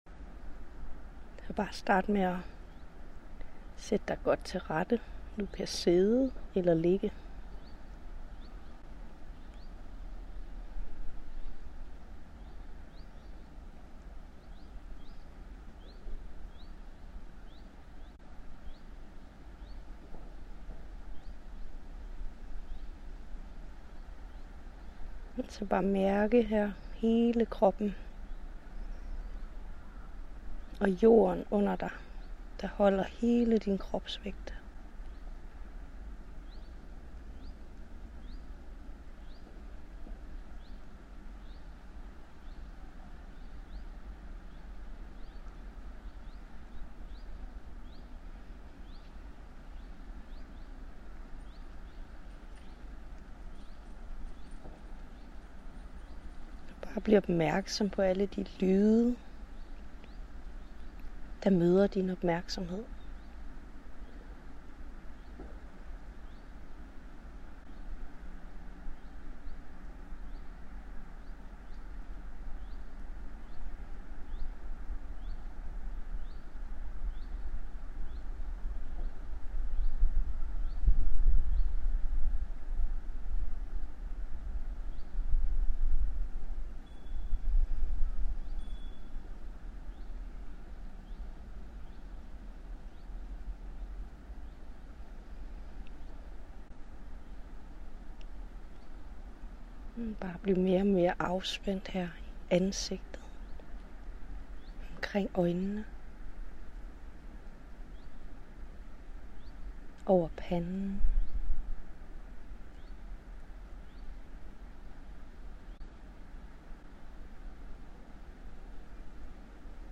Lyt til trommemeditation her
Tromme-meditation.mp3